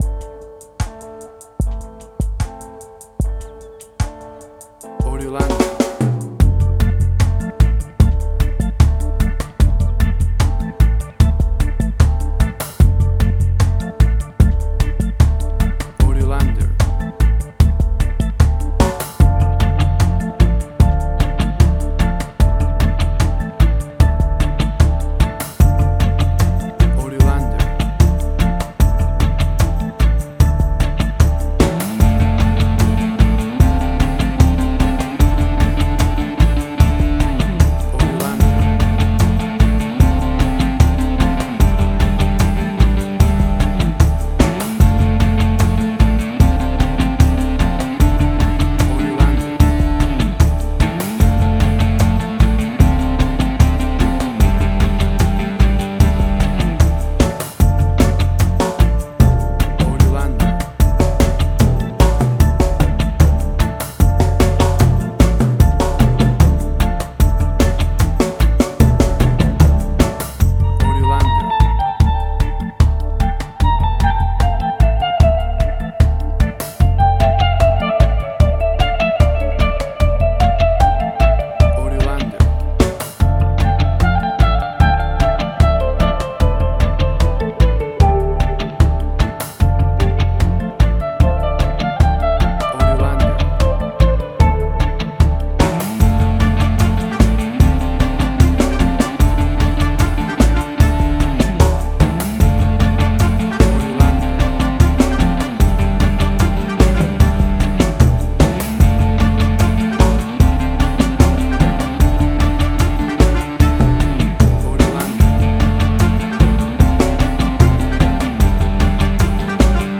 Reggae caribbean Dub Roots
WAV Sample Rate: 16-Bit stereo, 44.1 kHz
Tempo (BPM): 75